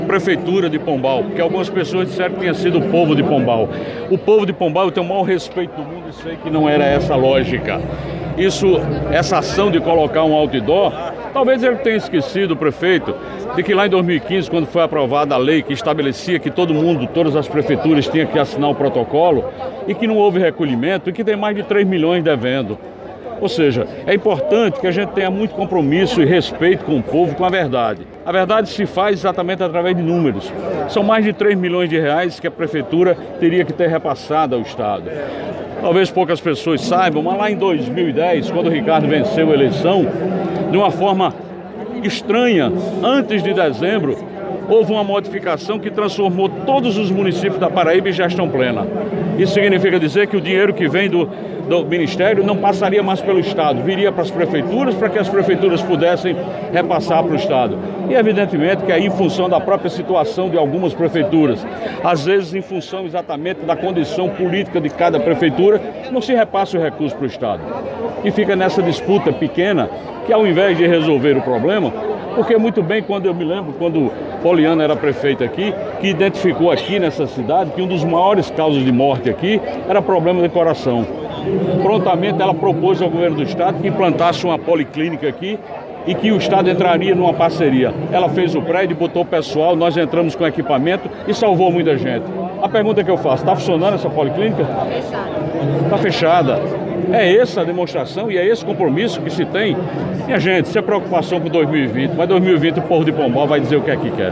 “É importante que agente tenha muito compromisso com o povo e com a verdade e a verdade se faz através de números. São mais de três milhões de reais que a prefeitura teria que ter repassado ao Estado”, disse durante entrevista.